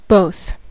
both: Wikimedia Commons US English Pronunciations
En-us-both.WAV